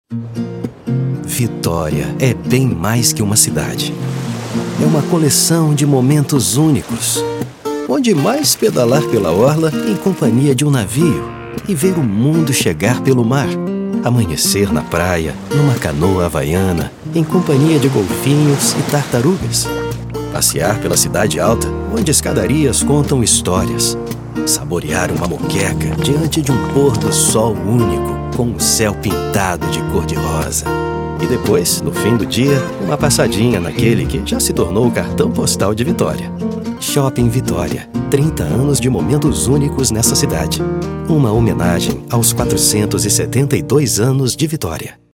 I've got a unique tone and can deliver a natural interpretation. My vocal range spans from 30 to 70 years old.
Sprechprobe: Werbung (Muttersprache):